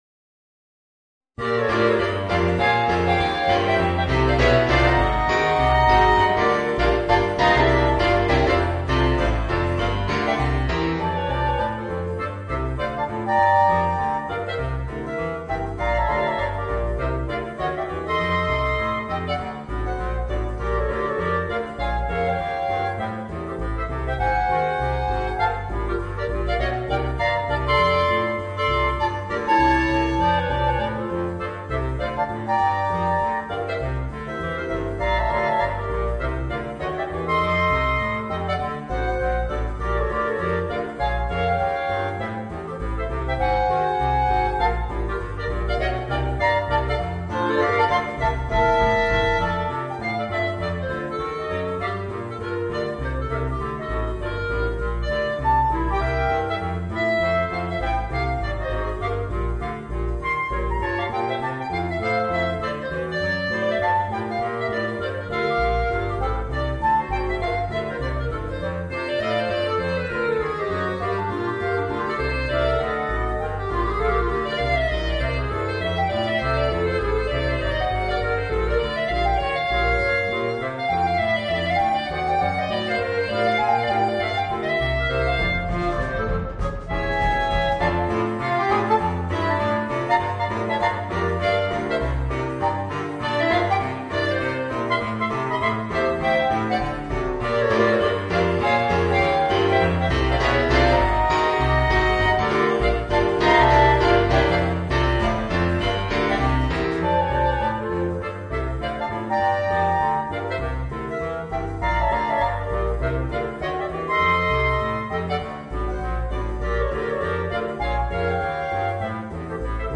Voicing: 4 Clarinets